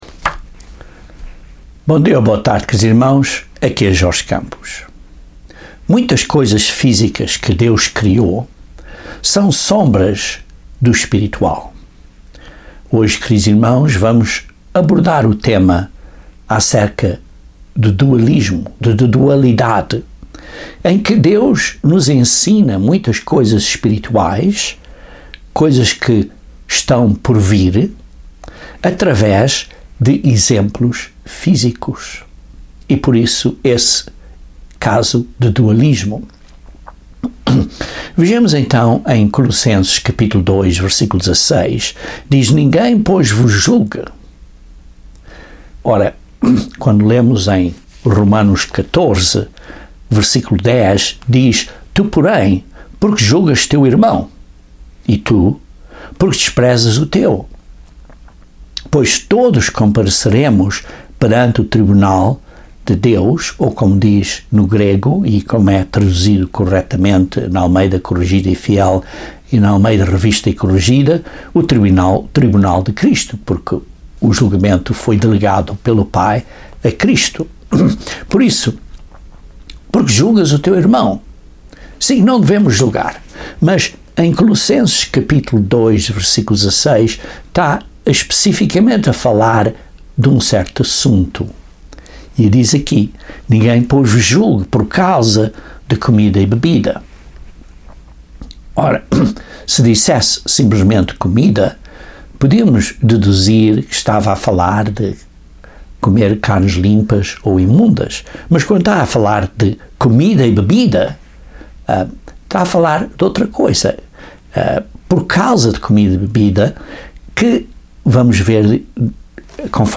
Muitos exemplos físicos têm uma dualidade, declarando lições espirituais. Este sermão analisa dois exemplos físicos que nos dão lições espirituais.